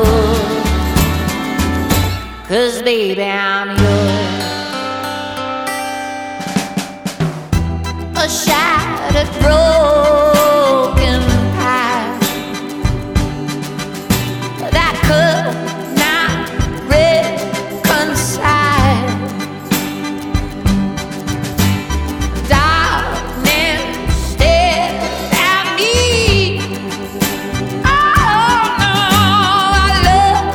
"templateExpression" => "Rock et variétés internationales"
0 => "Pop"